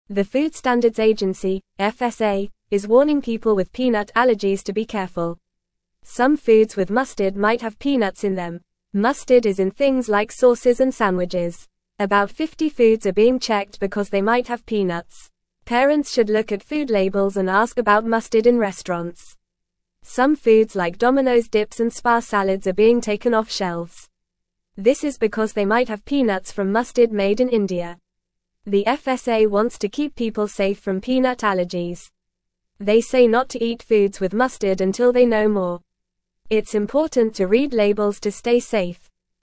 Fast
English-Newsroom-Beginner-FAST-Reading-Warning-for-Peanut-Allergies-Check-Foods-with-Mustard.mp3